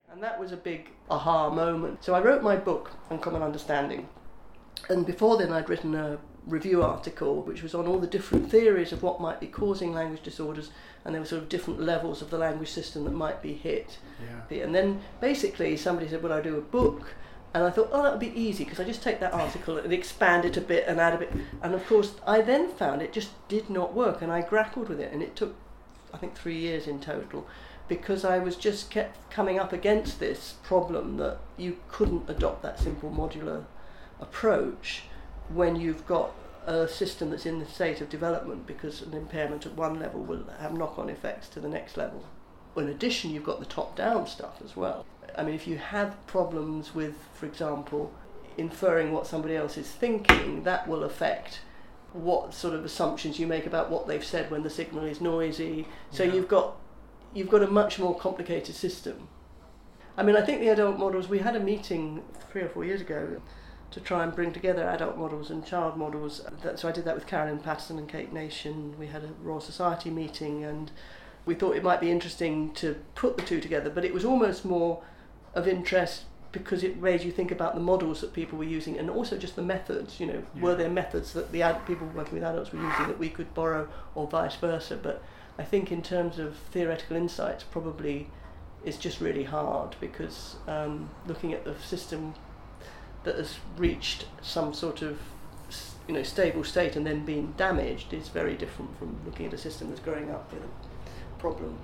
Here she recalls an “Ah-hah!” moment  in her theoretical approach and her efforts to find parallels between child and adult models of communication impairments: